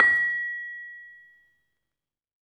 LAMEL A#5 -R.wav